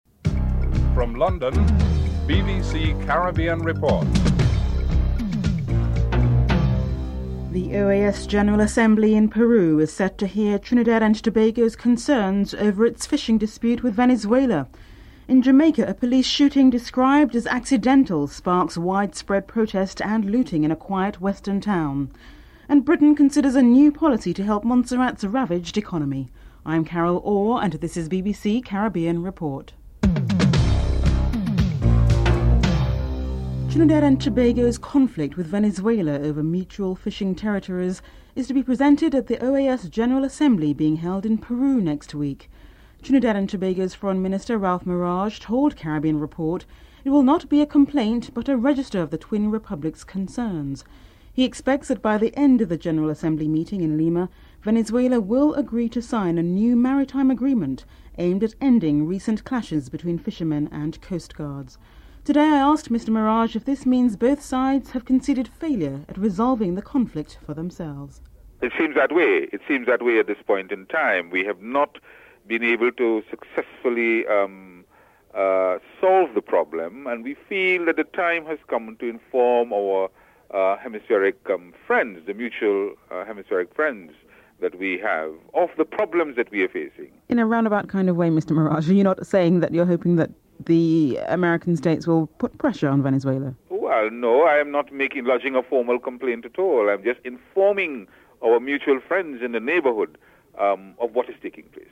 1. Headlines (00:00-00:32)
Foreign Affairs Minister, Ralph Maraj is interviewed (00:33-03:51)